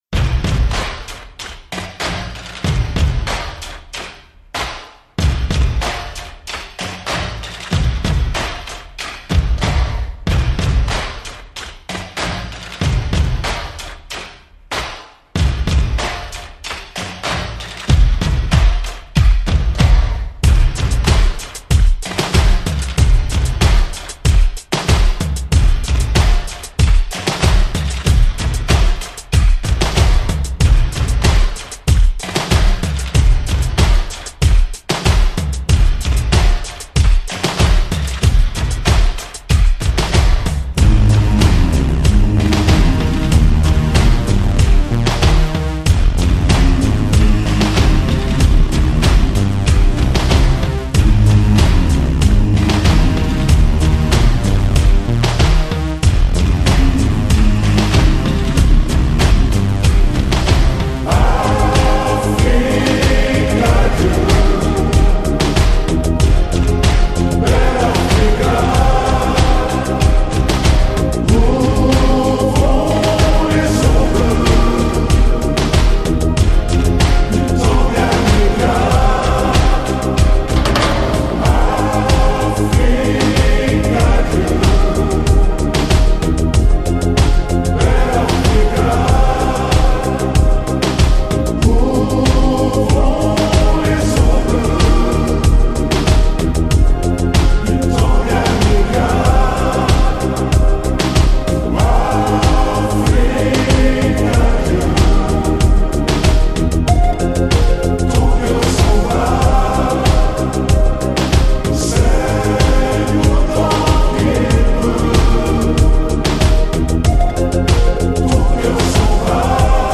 Sans la voix et ralenti c'est quand même mieux, enfin heu...